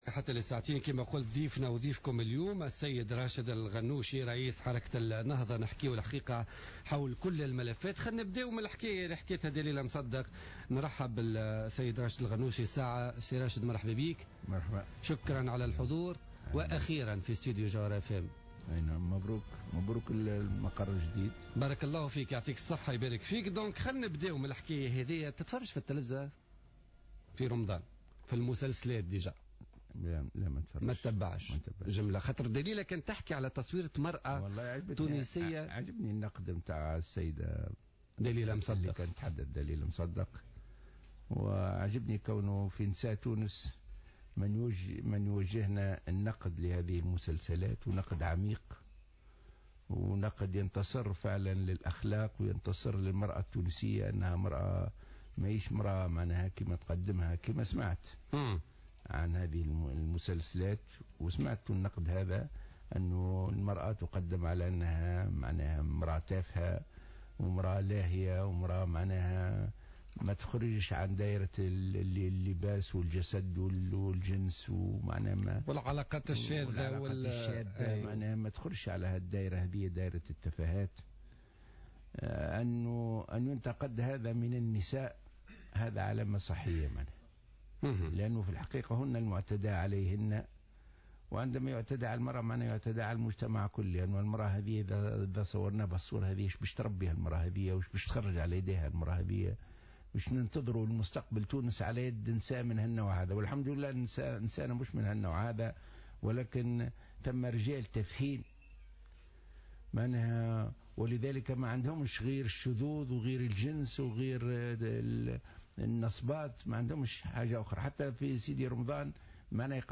Le président du mouvement Ennahdha Rached Ghannouchi a commenté ce jeudi 9 juillet 2015 lors de son passage sur les ondes de Jawhara FM dans le cadre de l’émission Politica, l’image de la femme tunisiennes, incarnée dans les feuilletons de Ramadan.